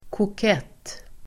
Ladda ner uttalet
Uttal: [kok'et:]